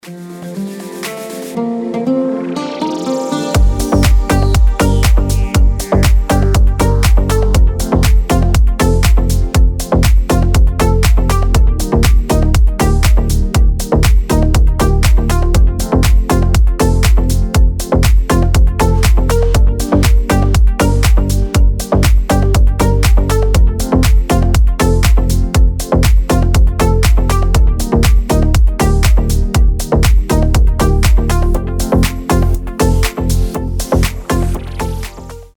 • Качество: 320, Stereo
deep house
красивая мелодия
nu disco